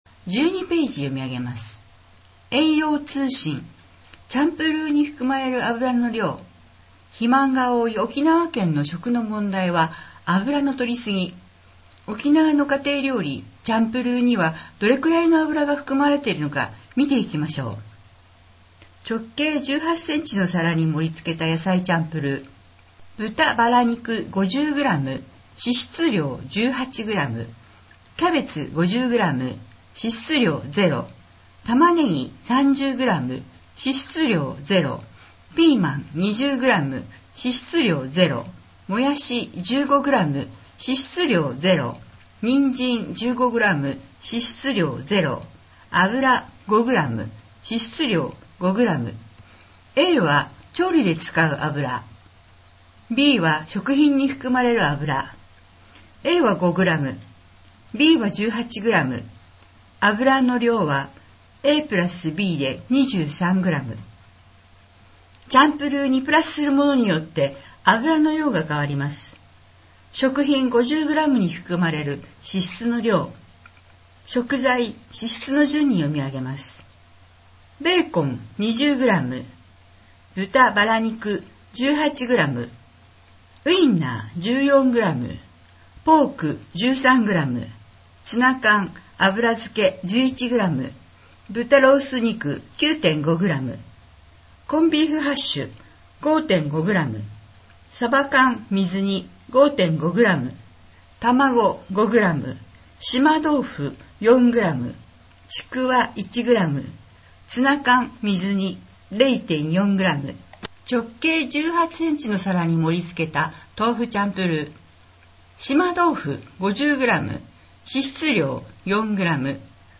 この音声は「音訳サークルやえせ」の皆さんのご協力で作成しています。